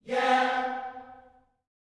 YEAH C 4F.wav